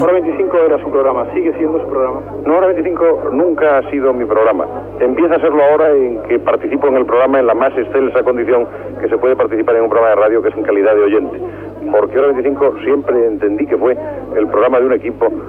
Retorn de la connexió als estudis de la Cadena SER a Madrrid.
Informatiu